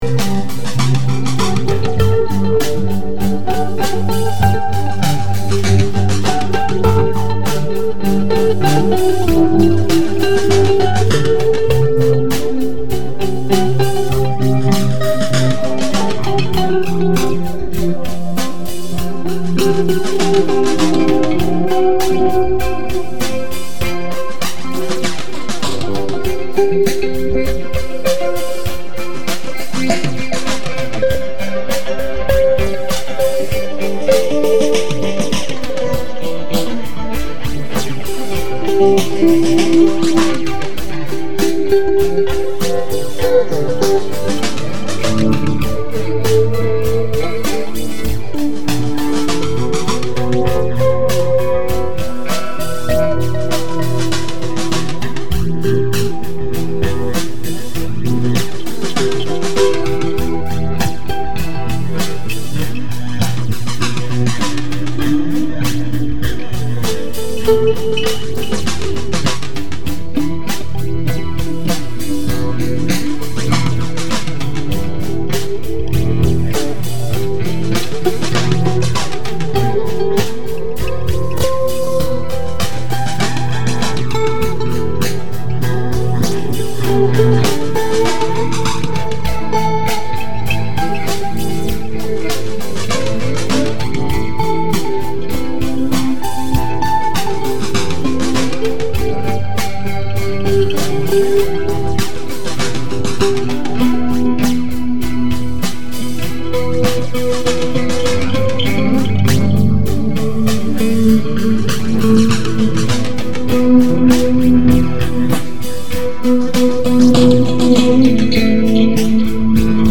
• Category: Sound 5.1